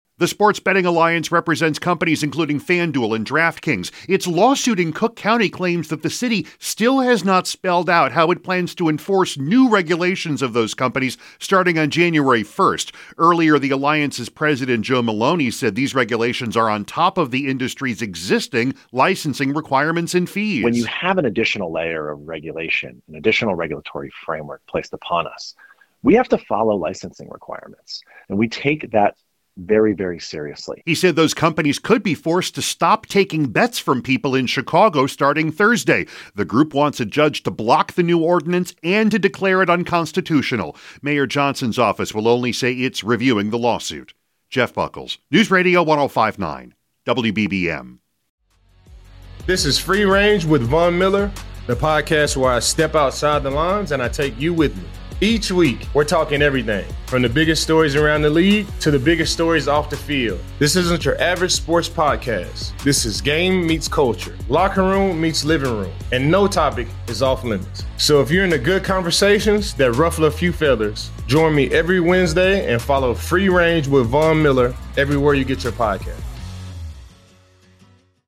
A dive into the top headlines in Chicago, delivering the news you need in 10 minutes or less multiple times a day from WBBM Newsradio.